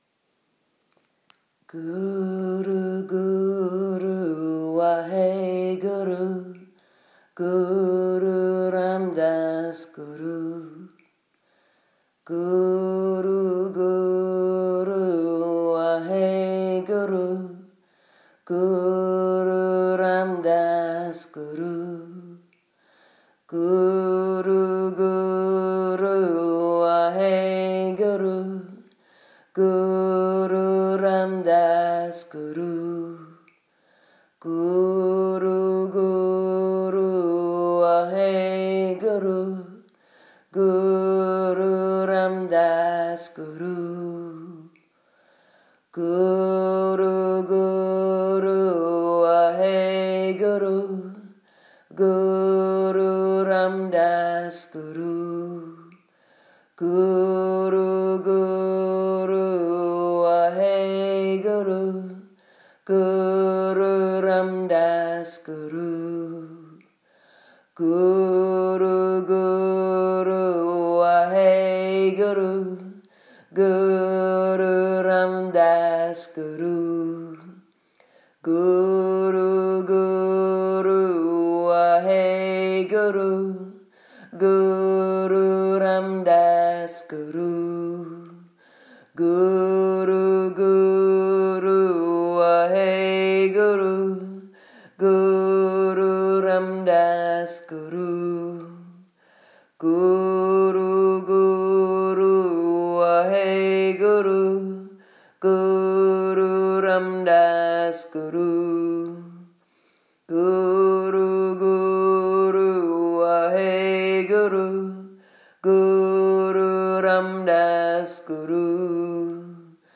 So if you can not join us , or had the intention but found your duvet more inviting I share with you 5 mins of the Guru Ramdas Mantra in a non religious but highly up lifting form.